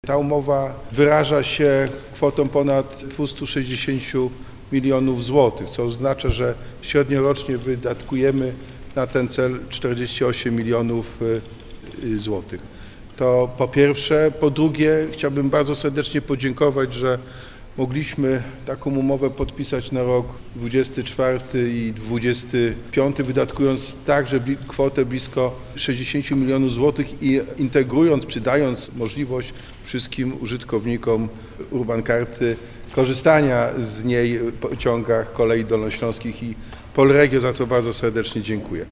Kontynuacja honorowania URBANCARD w pociągach to decyzja poprawiająca mobilność tysięcy mieszkańców – mówi Jacek Sutryk, prezydent Wrocławia.